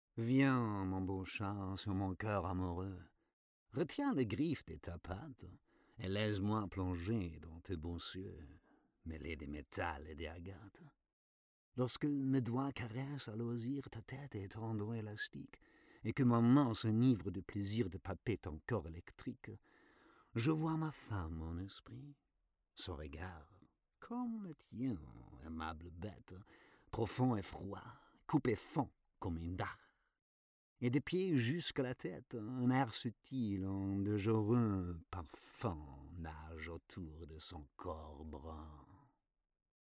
Sprecherdemos
Poetik: Baudelaire